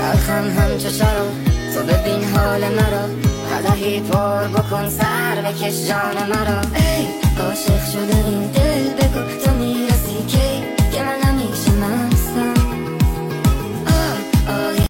ریمیکس با صدای بچه